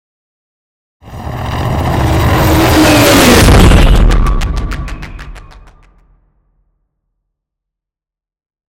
Scifi passby whoosh long
Sound Effects
futuristic
pass by
vehicle